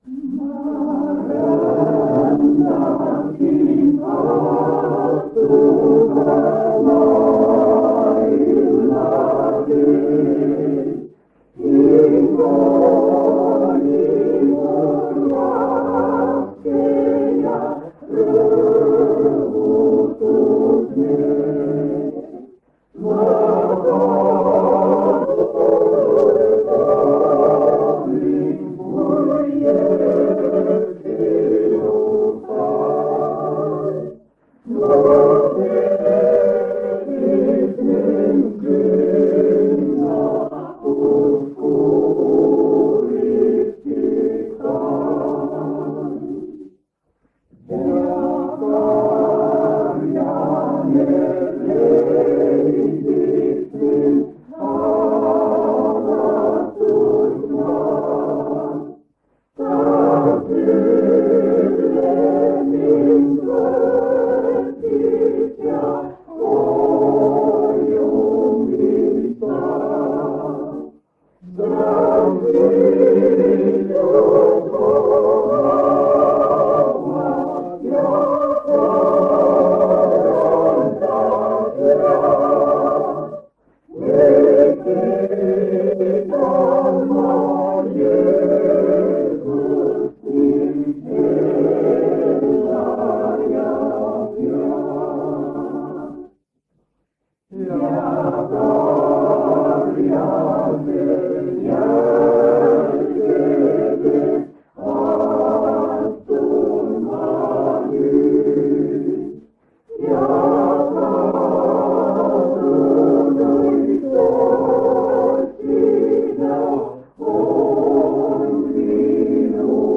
Koosolekute helisalvestused
On 1977 aasta kevad. Paide adventkirikus toimub
Täpsemaid kuupäevi pole teada ning jagasin lintmaki lintidele talletatu kuueks päevaks.